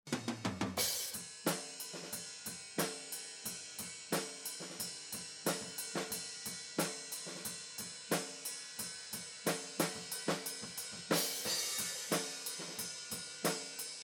écoutant donc la piste overhead en stéréo apart :